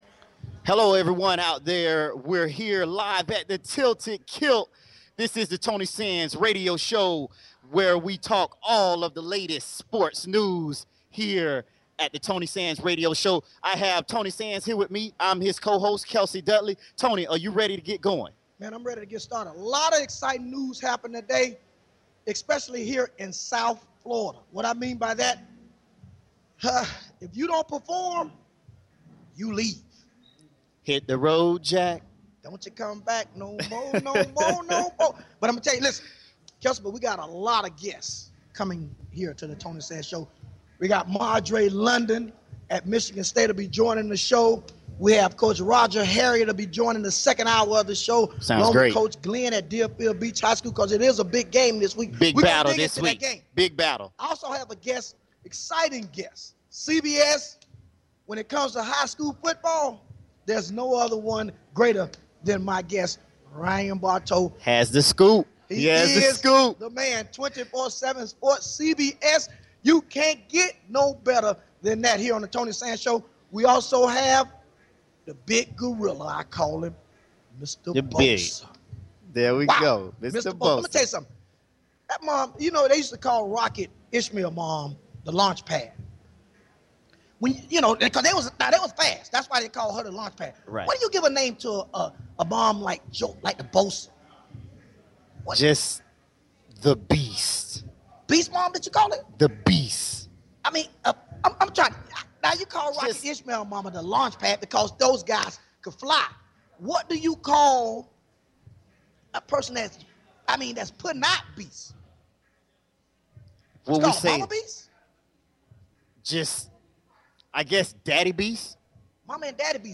Talk Show Episode
Live from Tilted Kilt